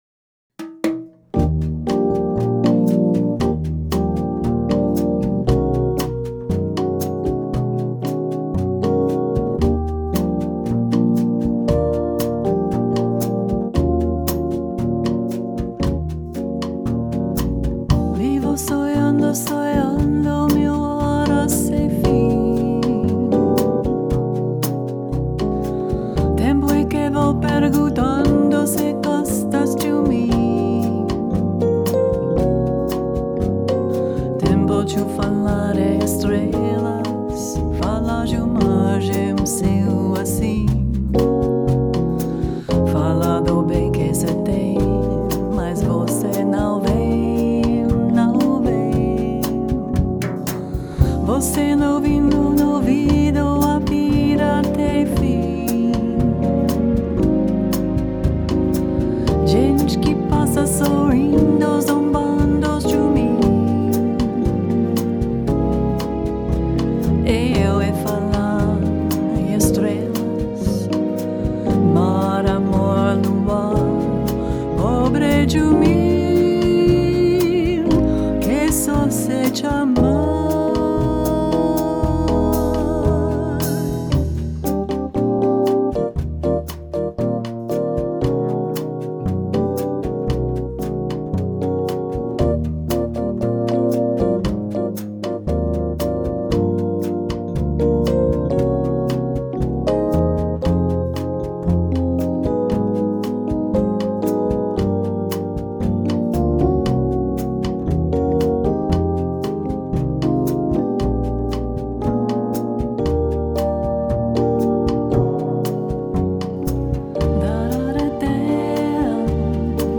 Suite 16 Recording Studio